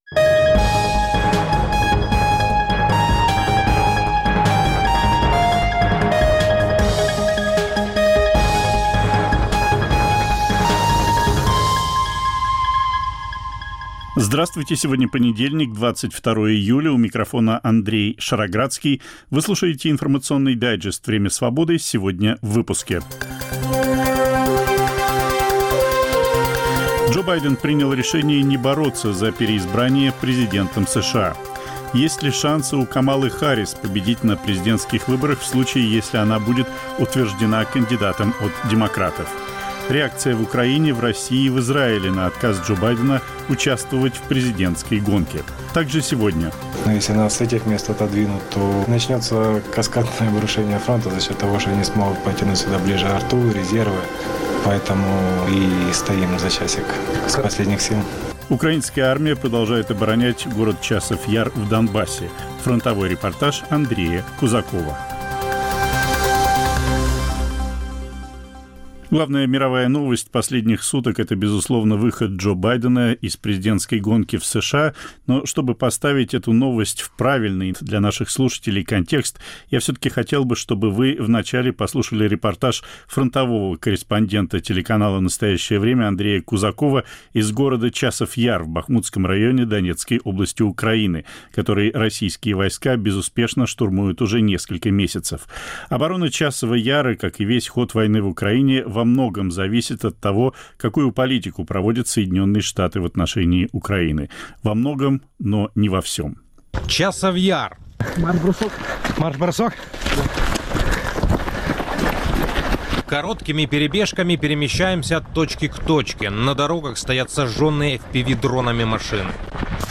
Репортаж из Часова Яра